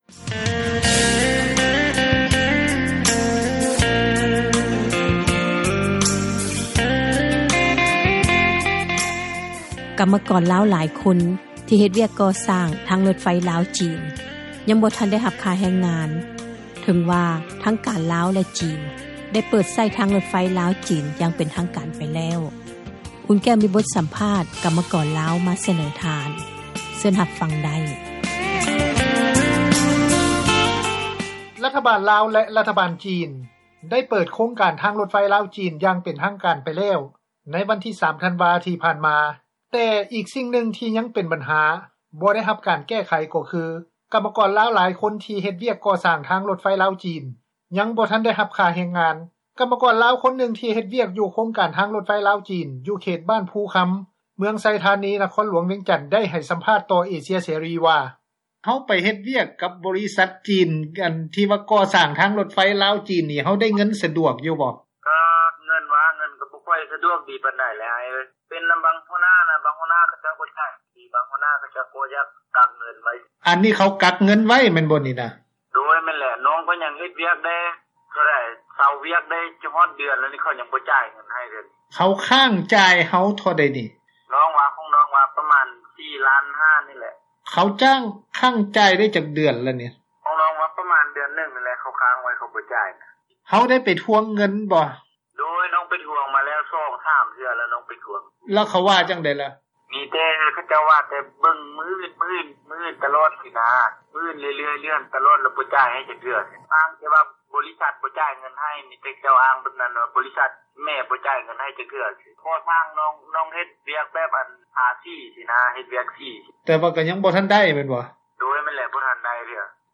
ກັມກອນລາວ ຄົນນຶ່ງທີ່ເຮັດວຽກ ຢູ່ໂຄງການທາງຣົໄຟລາວ-ຈີນ ຢູ່ເຂດບ້ານ ພູຄຳ ເມືອງໄຊທານີ ນະຄອນຫລວງວຽງຈັນ ໄດ້ໃຫ້ສຳພາດຕໍ່ເອເຊັຽເສຣີ ວ່າ: